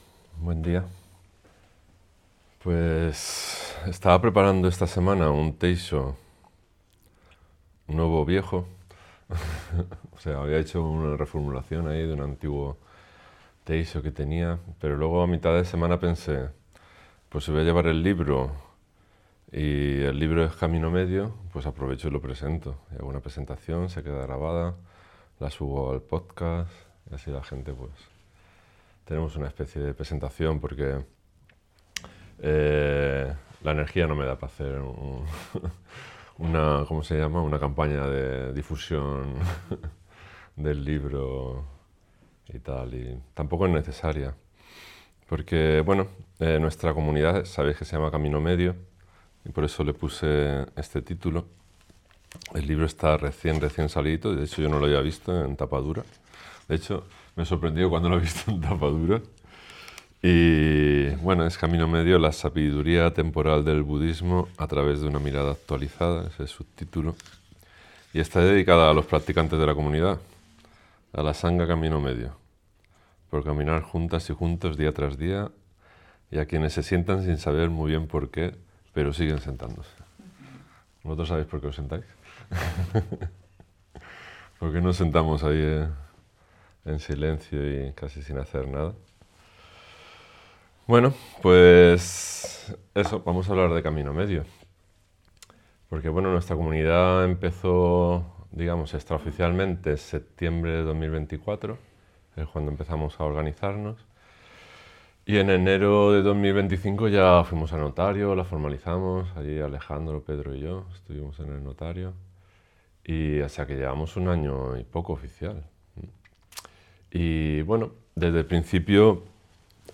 Presentación del libro Camino Medio